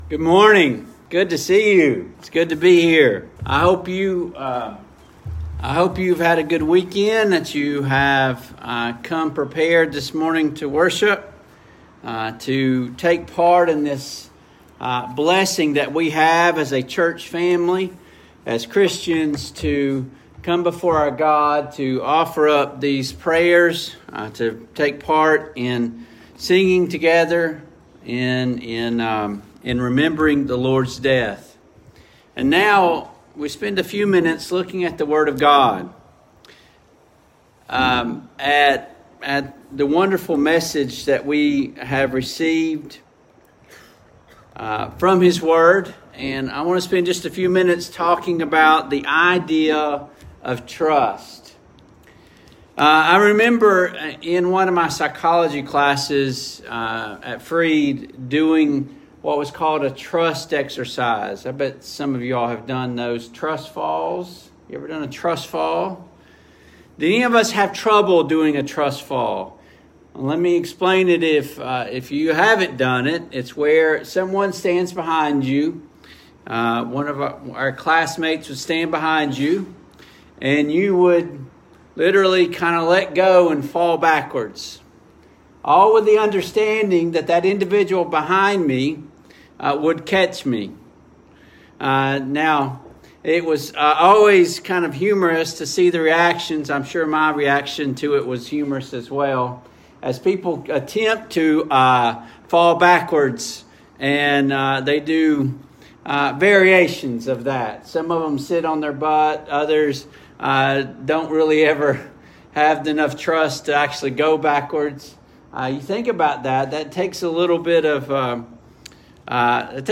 Service Type: AM Worship Topics: Faith , Trust